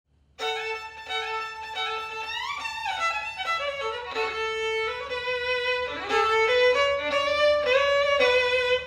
Bathrooms have the best acoustics!